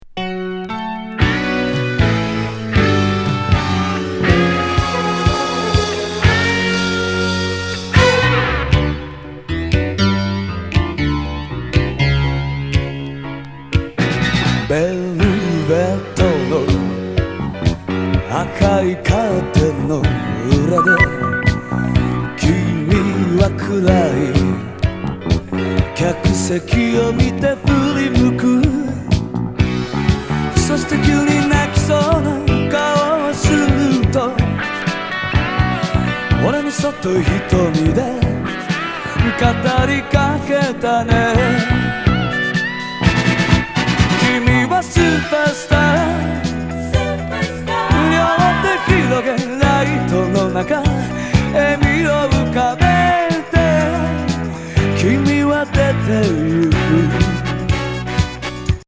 BPM122。
派手目強力ディスコ・ロック